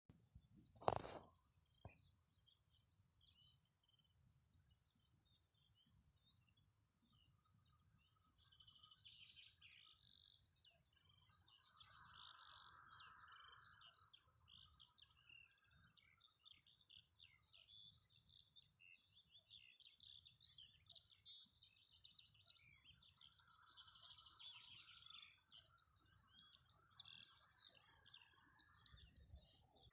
Птицы -> Жаворонковые ->
полевой жаворонок, Alauda arvensis
СтатусПоёт
ПримечанияAtklāta lauku ainava